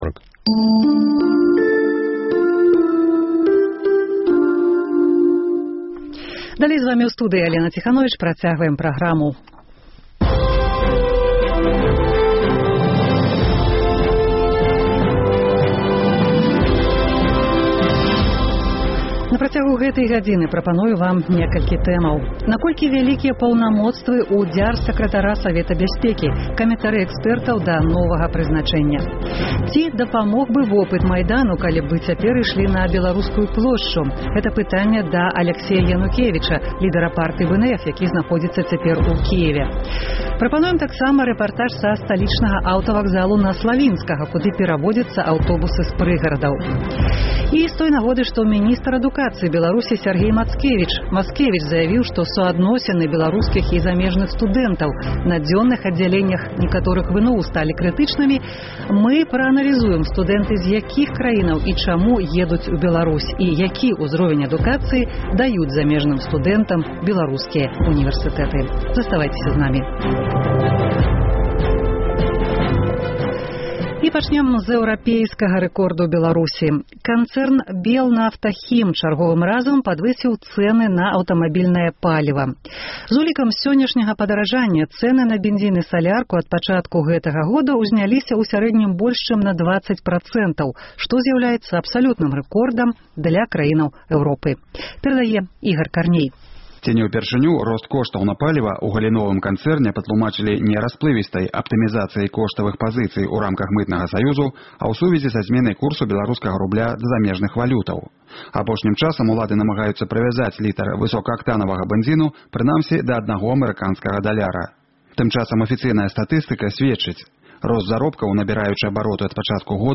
Рэпартаж са сталічнага аўтавакзалу на вуліцы Славінскага, куды пераводзяцца аўтобусы з прыгарадаў.